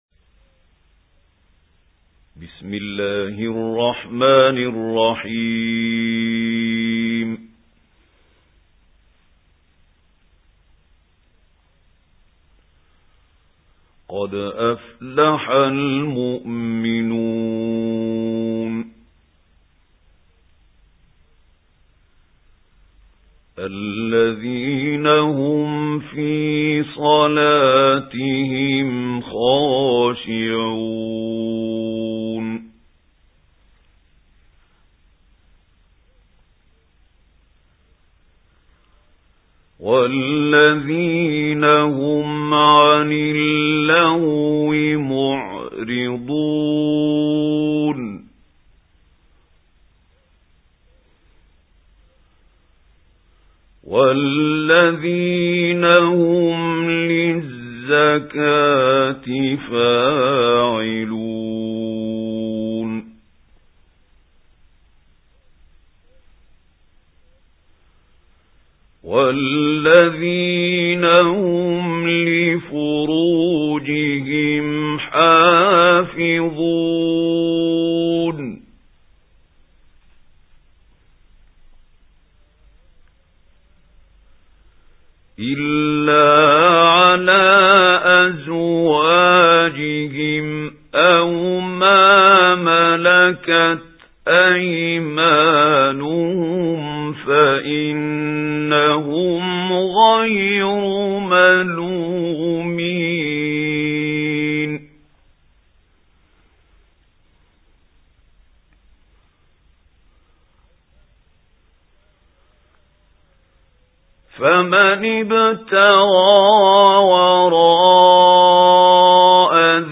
سُورَةُ المُؤۡمِنُونَ بصوت الشيخ محمود خليل الحصري